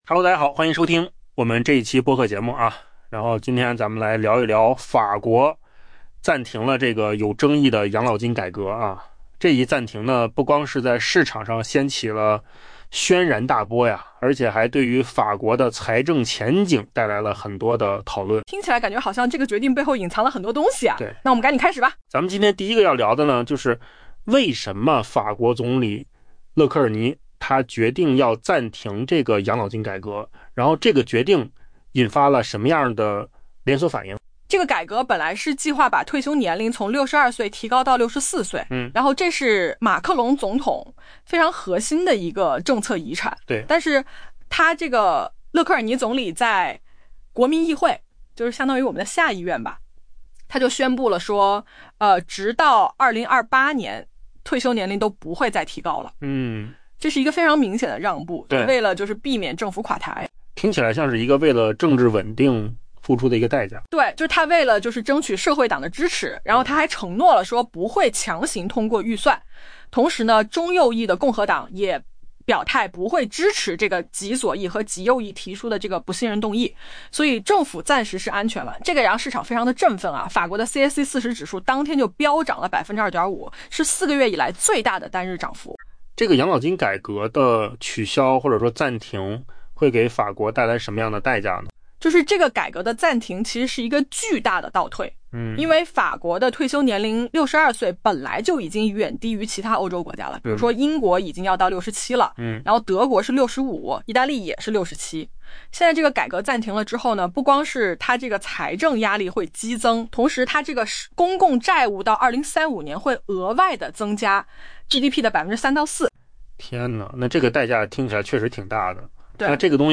AI 播客：换个方式听新闻 下载 mp3 音频由扣子空间生成 法国总理勒科尔尼暂停一项备受争议的养老金改革的决定，在周三为市场带来了一些可喜的喘息之机，此举似乎至少暂时避免了又一次政府垮台。